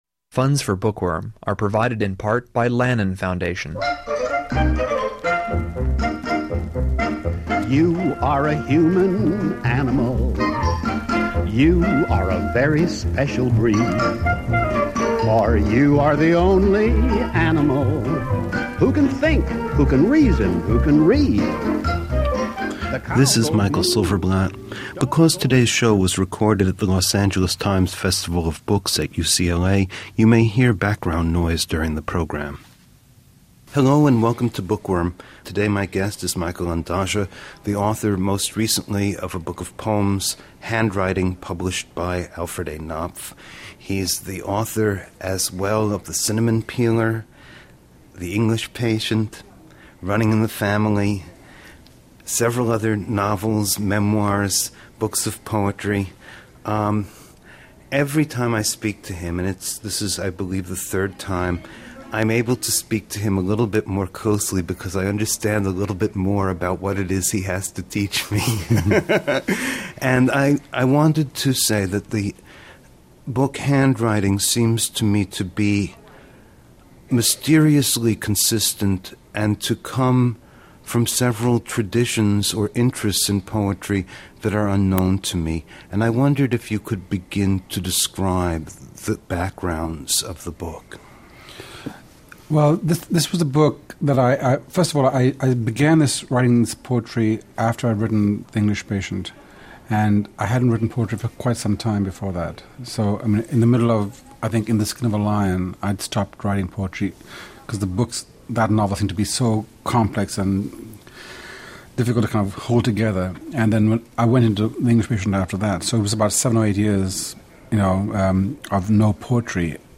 In his most revealing interview so far, Michael Ondaatje, discussing his poetry, explores the mystery of language itself--the language of his birth, its ancient poetry and mythologies.